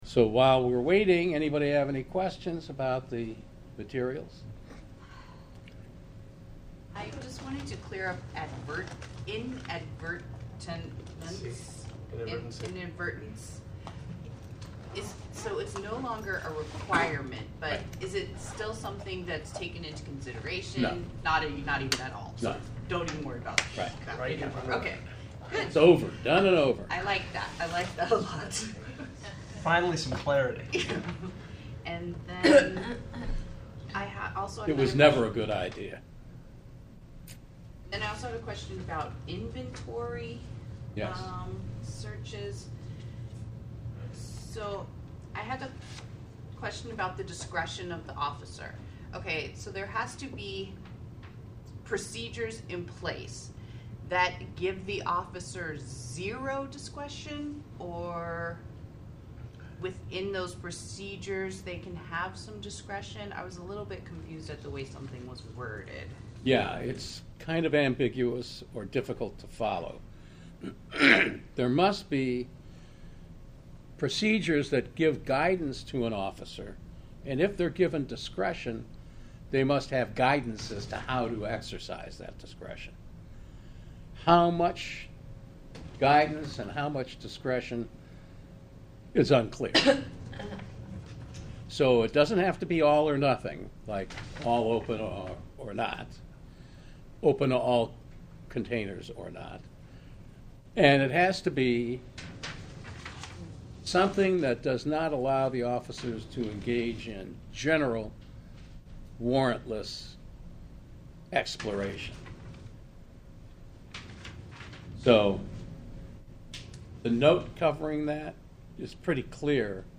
The recordings are of only the beginning of class, when I answered questions